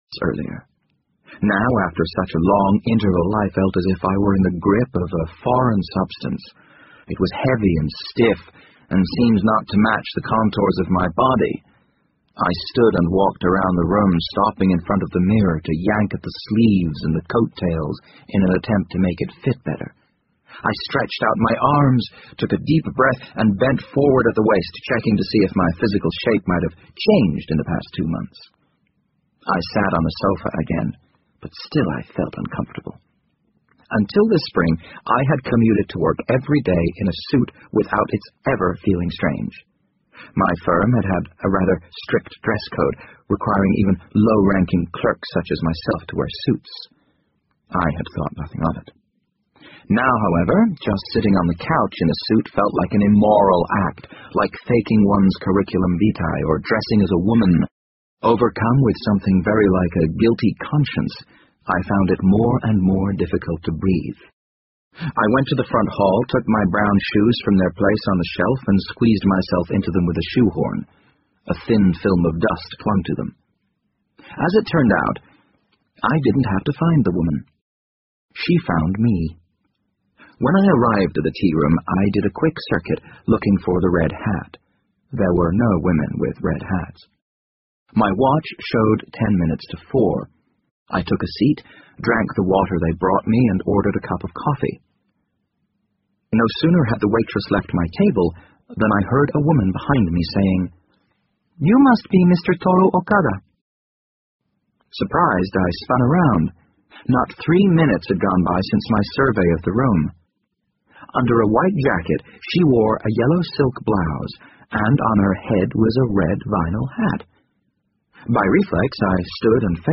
BBC英文广播剧在线听 The Wind Up Bird 17 听力文件下载—在线英语听力室